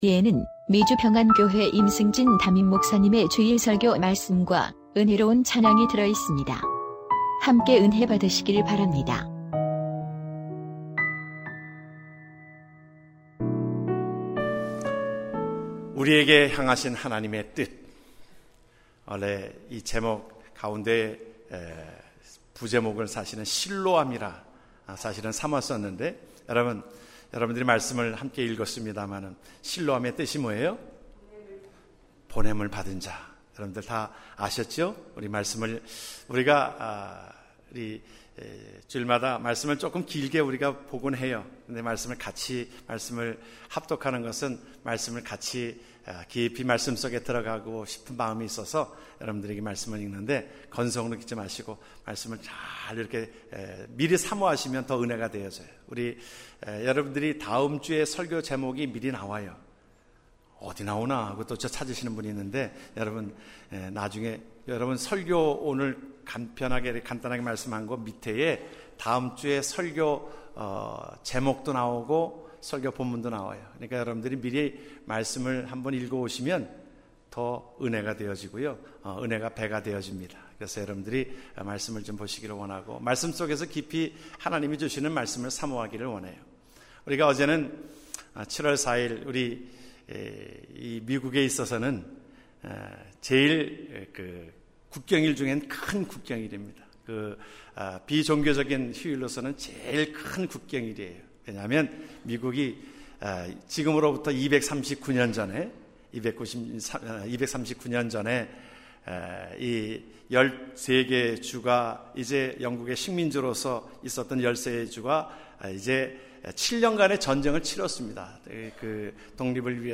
주일설교말씀